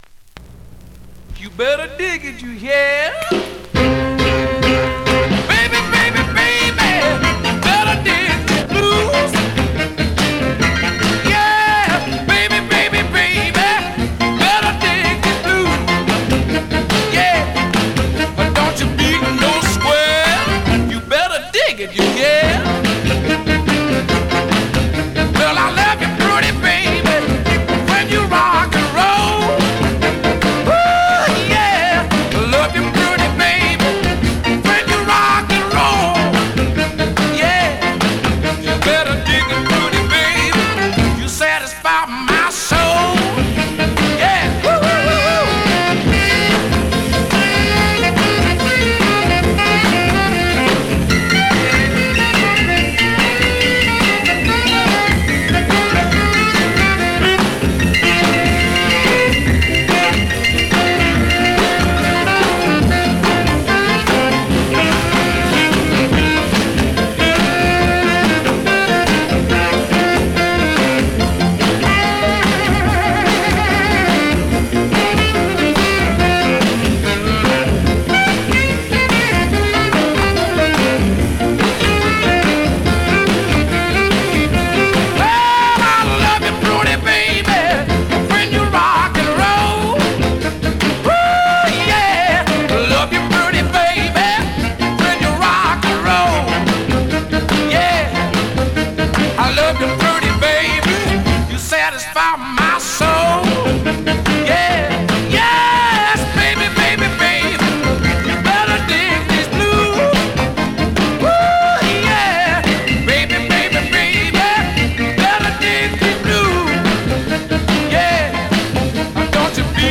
Great up-tempo Rnb / Mod dancer .